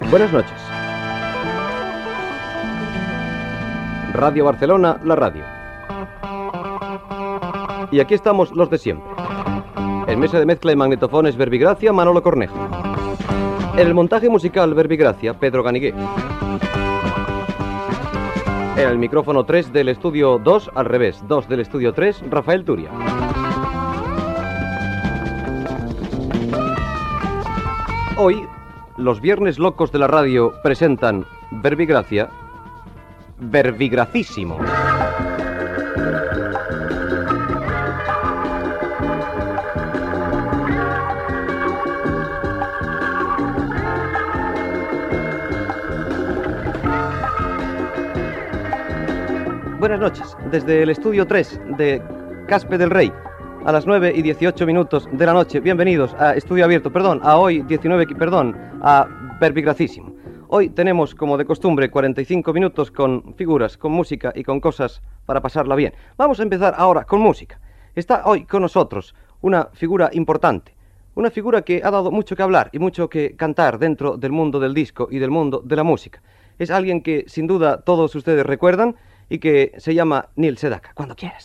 Inici del programa, presentació, equip i una imitació del presentador José María Íñigo.
Musical